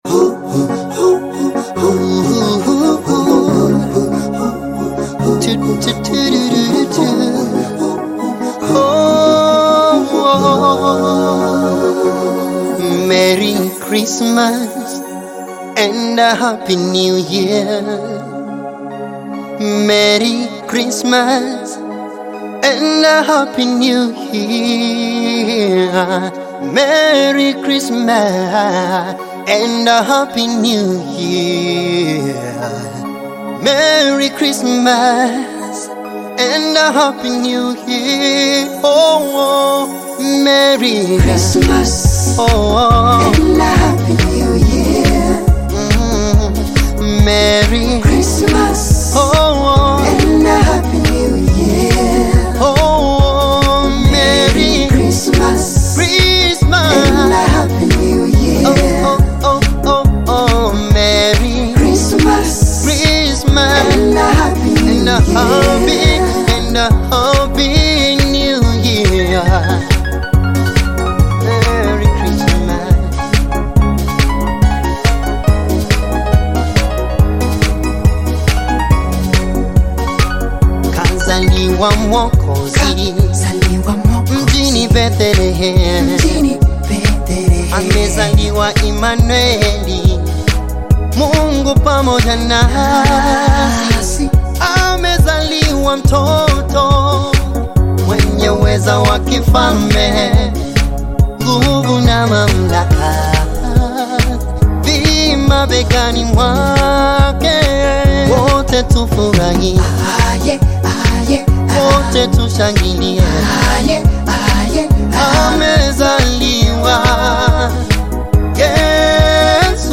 Tanzanian gospel recording artist
Christmas song